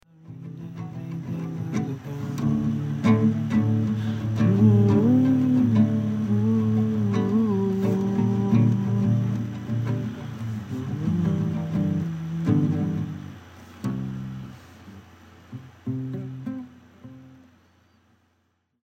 You can hear it in this memo below from the day I started it – ungracefully stumbling into it, but it’s there.
The first version of this song was a lot more frustrated.
Musically, this song has a little bit of late-90’s/early 00’s angst in it, and it was like that immediately.